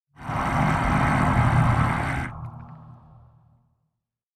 Minecraft Version Minecraft Version 25w18a Latest Release | Latest Snapshot 25w18a / assets / minecraft / sounds / mob / warden / angry_6.ogg Compare With Compare With Latest Release | Latest Snapshot
angry_6.ogg